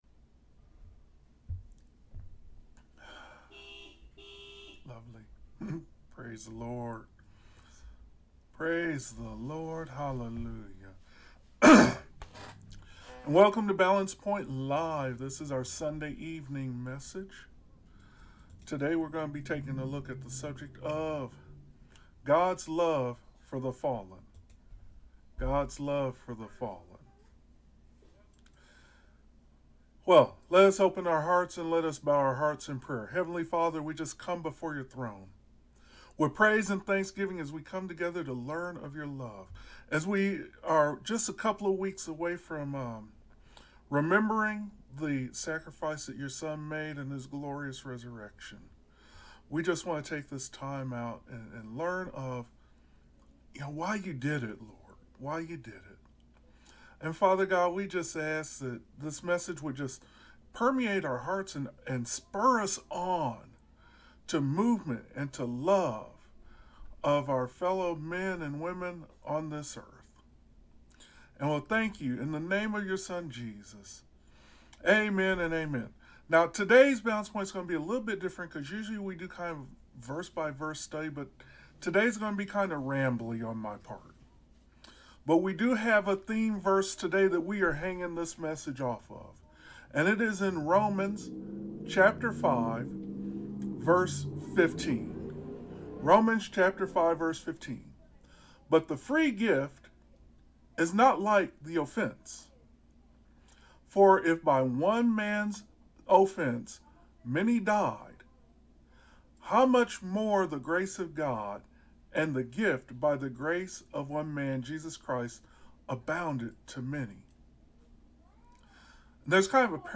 Sermons – Page 6 – Balance Point Christian Community
Service Type: Thursday